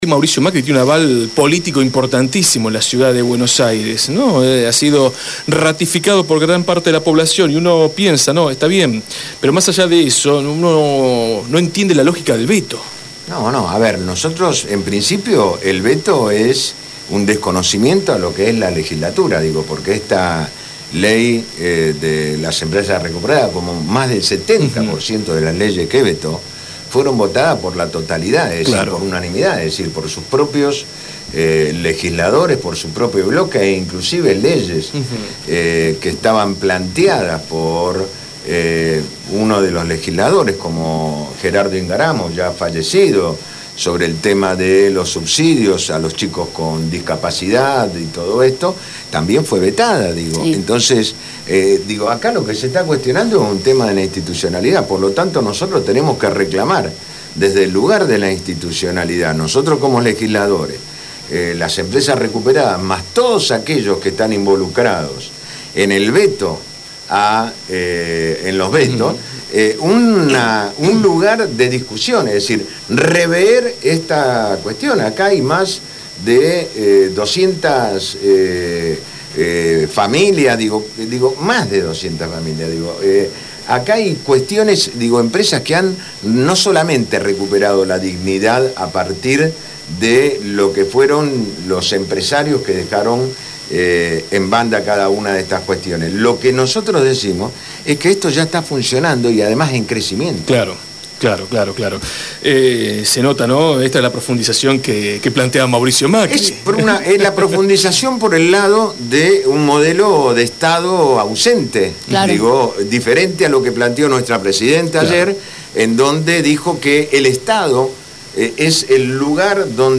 Tito Nenna, legislador porteño, estuvo en los estudios de Radio Gráfica minutos antes de comenzar la conferencia de prensa contra el veto a la ley de las empresas recuperadas en la Gráfica Patricios.
Tito Nenna – Legislador porteño